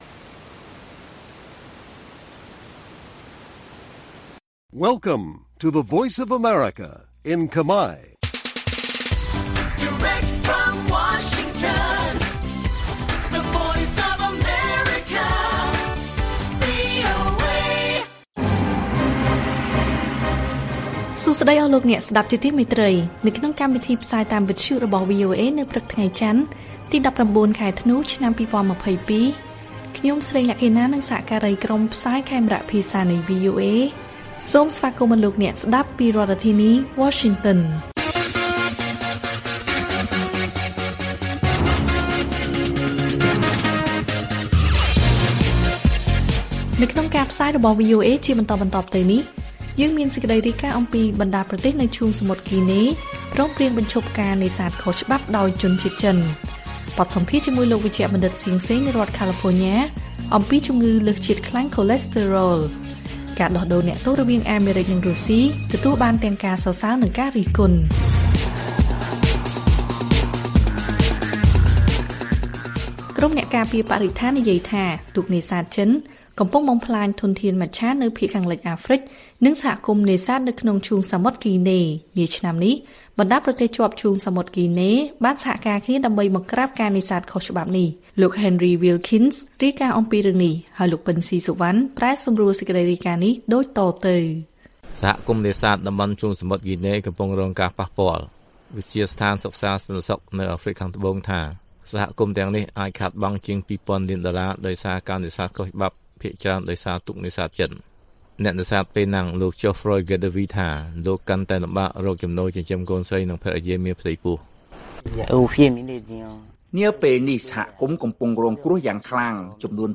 ព័ត៌មានពេលព្រឹក